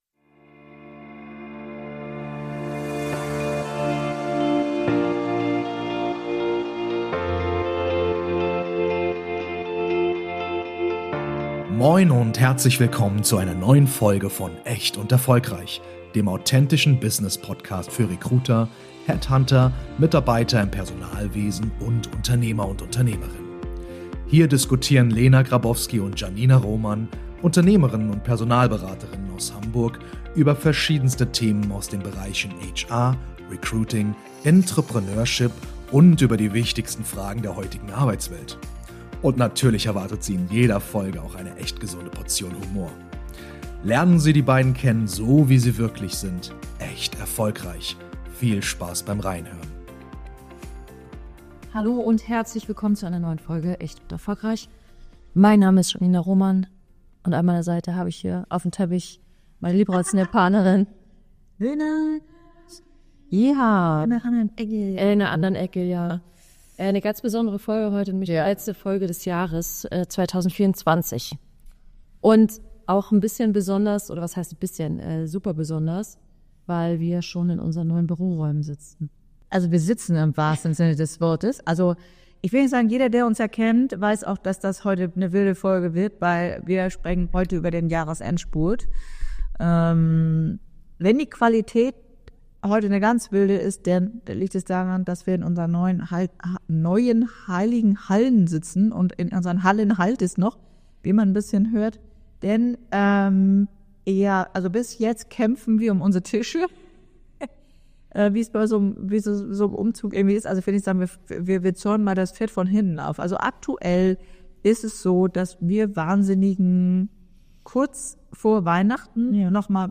Wir melden uns mit einem Jahresrückblick aus unserem neuen Office!